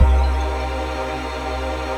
ATMOPAD26.wav